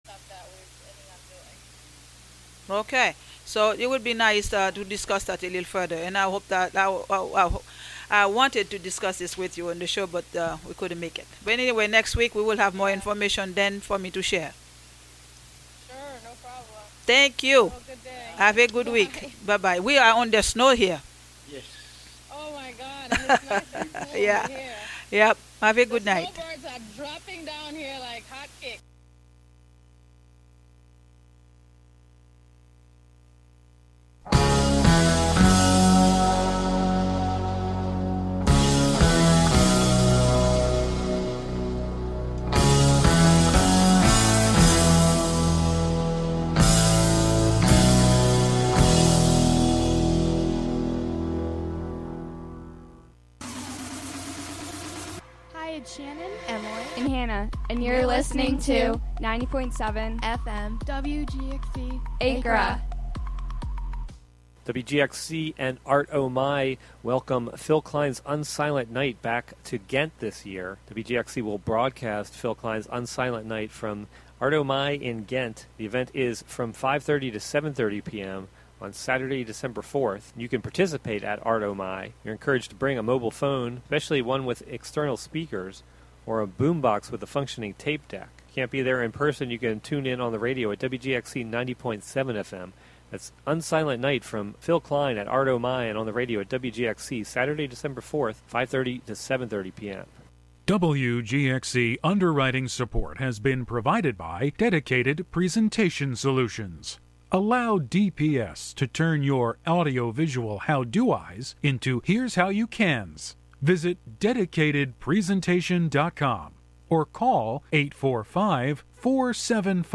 Monthly program featuring music and interviews from Dutchess County resident broadcast live from WGXC's Hudson studio.